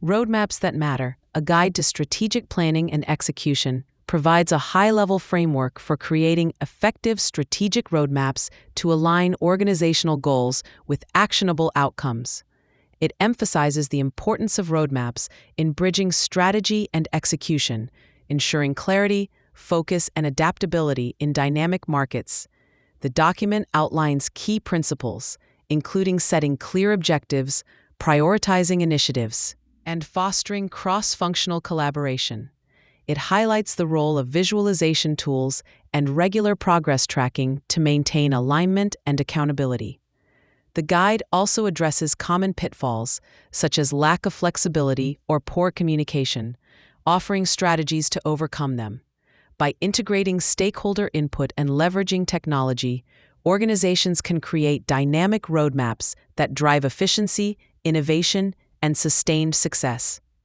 Roadmaps_that_matter_AI_overview.mp3